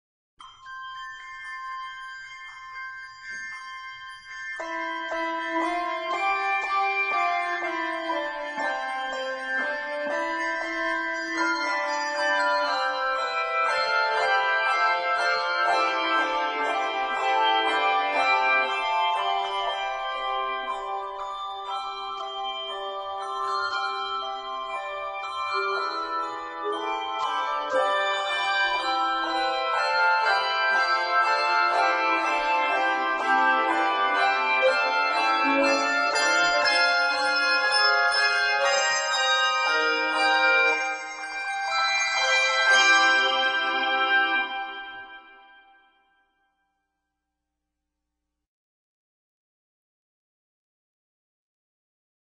are both energetic and fully scored for maximum effect.